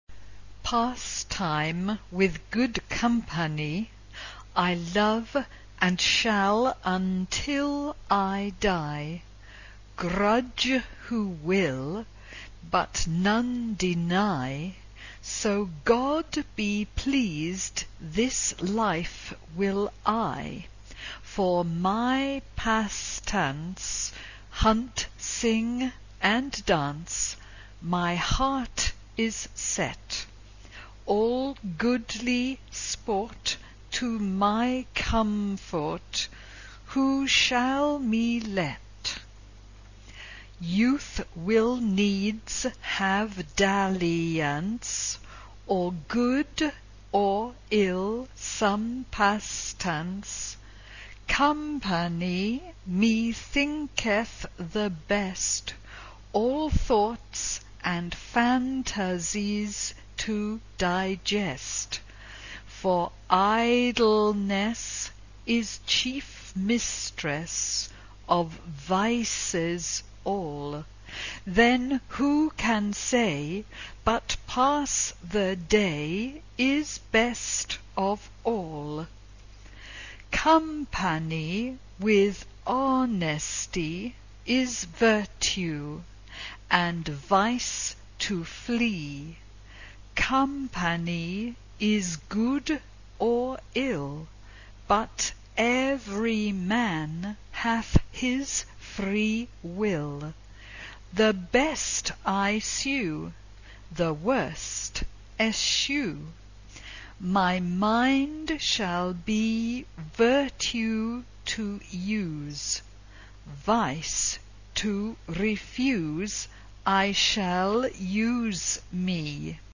Genre-Style-Form: Secular ; Renaissance ; Courtly song Type of Choir: SAB (3 mixed voices )
Tonality: A minor